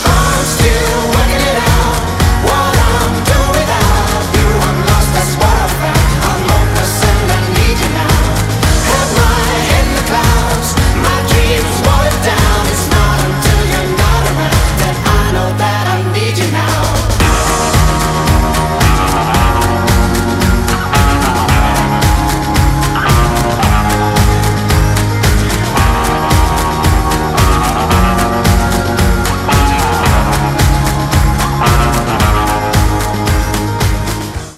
• Качество: 192, Stereo
мужской вокал
электрогитара
Pop Rock
Folk Rock
alternative
банджо
авторская песня